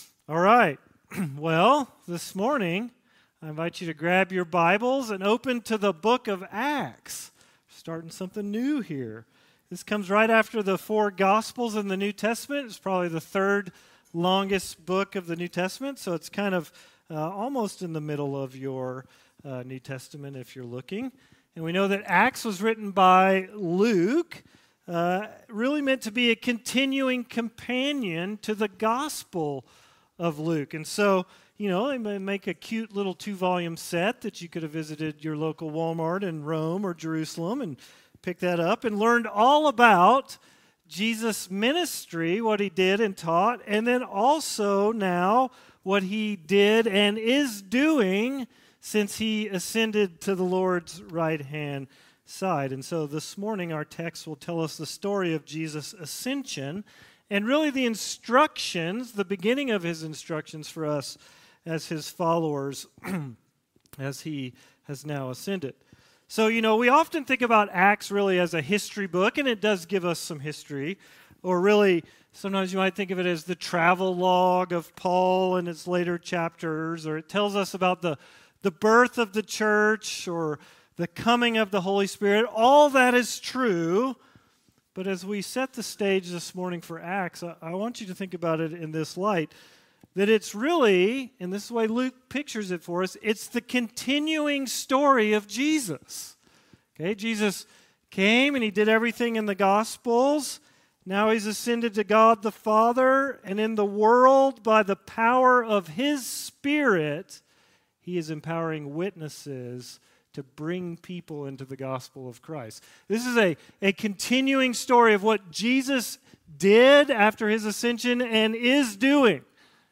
Current Sermon Series - Risen Life Church